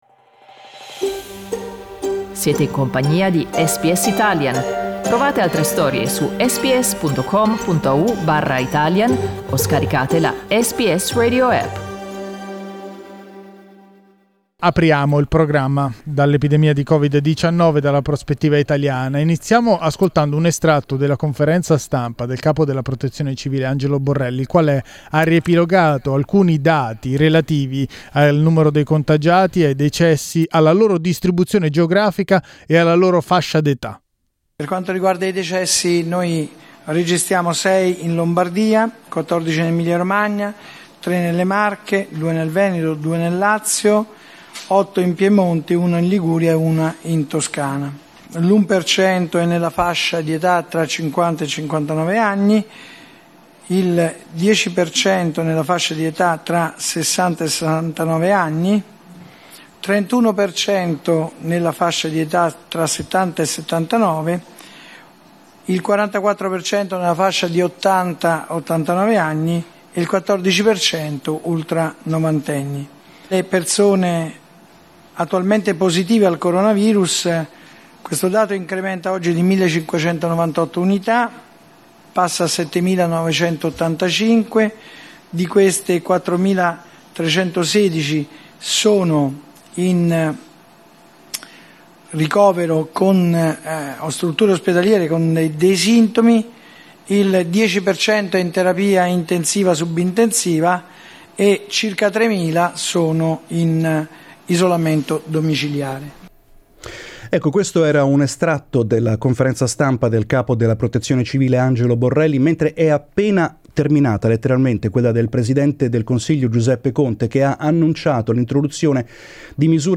Ne abbiamo parlato, a pochi minuti dall'annuncio, con il corrispondente da Milano